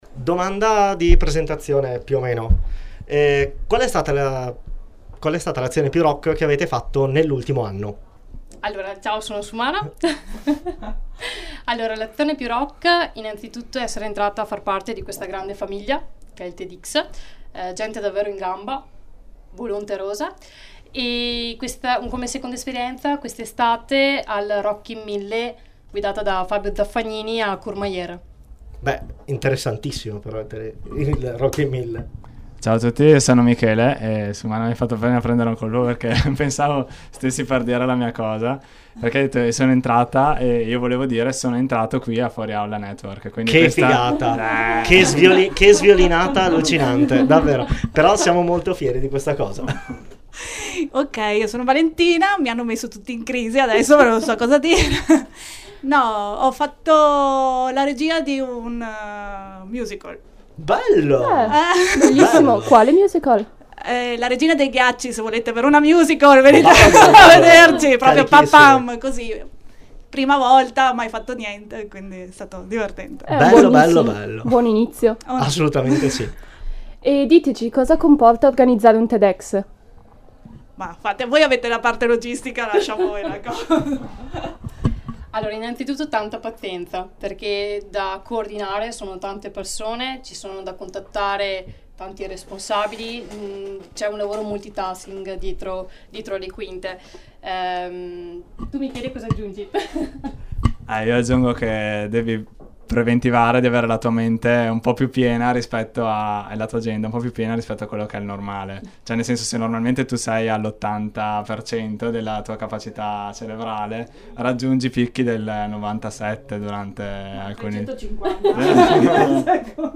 Non perdetevi questa super intervista ed i numerosi appuntamenti di sabato 7 e domenica 8 ottobre.